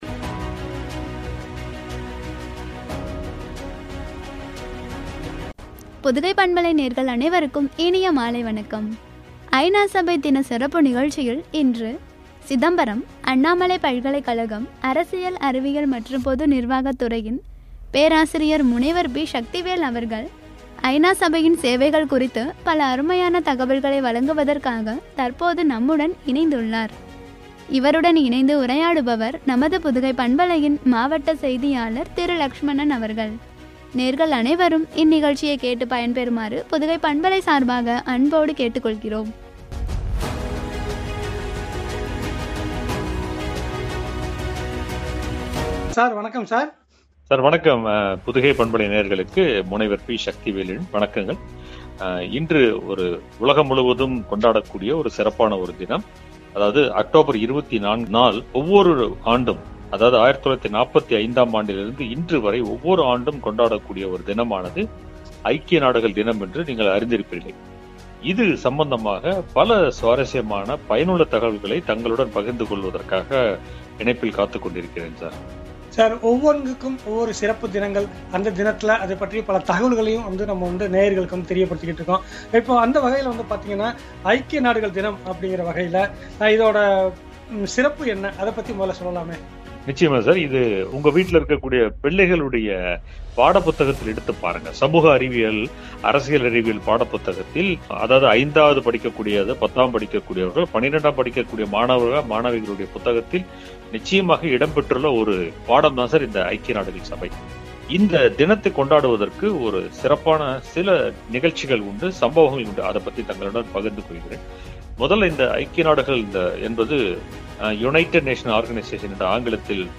சபையின் சேவைகள்” என்ற தலைப்பில் வழங்கிய உரையாடல்.